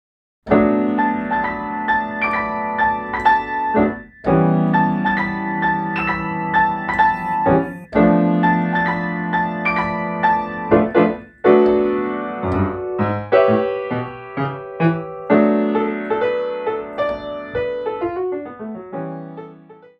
Piano Solo
10 minimalist pieces for Piano.